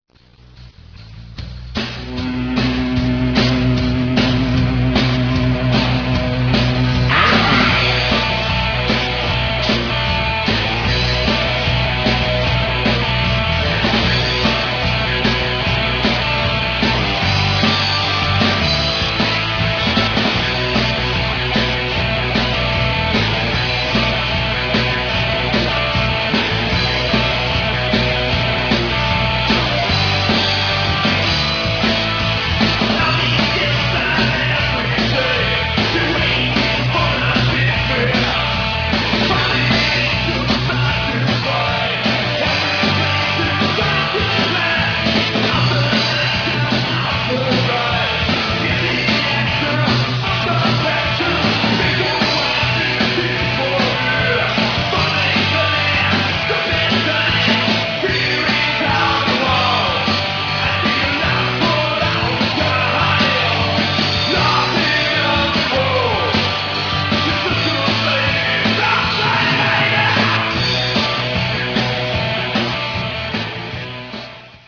Third World Glam Rock Band